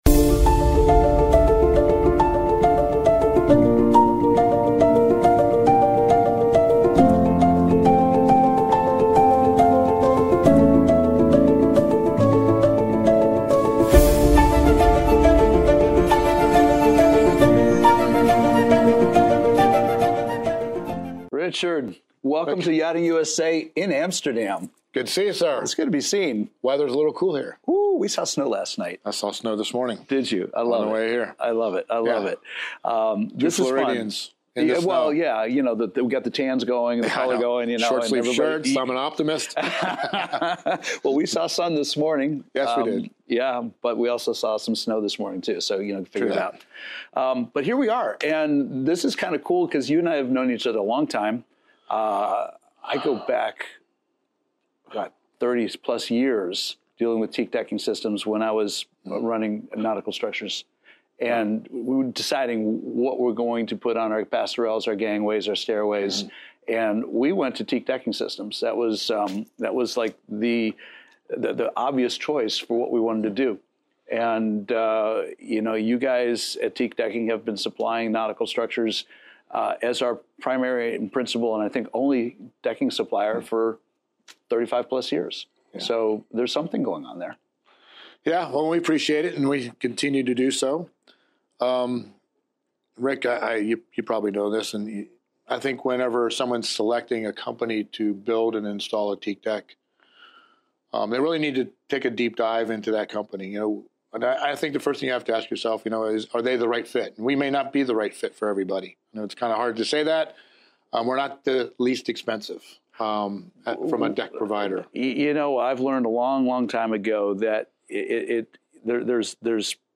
in an insightful discussion at METSTRADE in Amsterdam